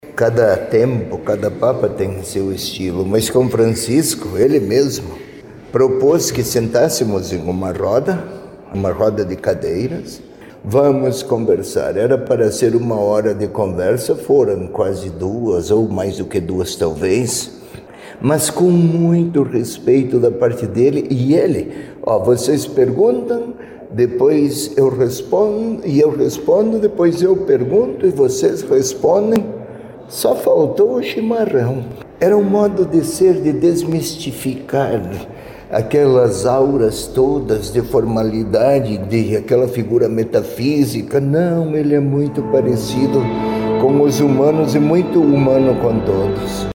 O arcebispo metropolitano foi nomeado pela Papa Francisco em 2015 e contou como era o Santo Padre, muito diferente de seus antecessores, com menos formalidades.